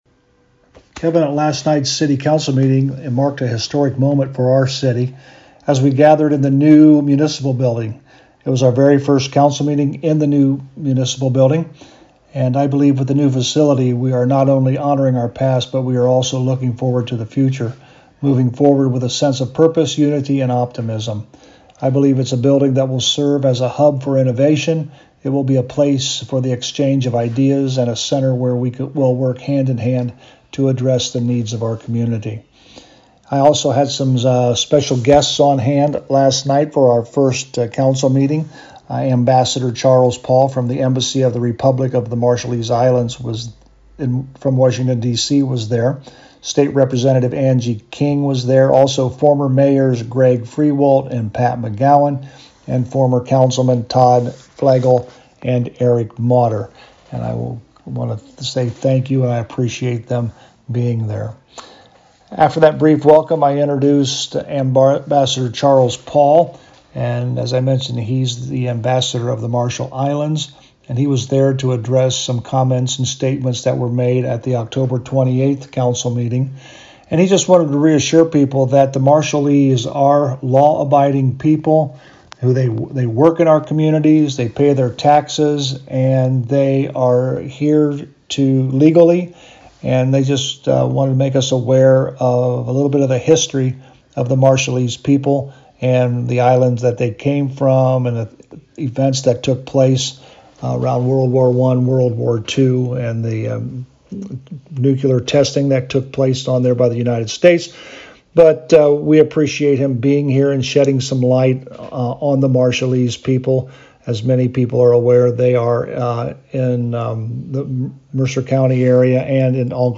Local News
To hear the summary of the November 11th St Marys City Council with Mayor Joe Hurlburt: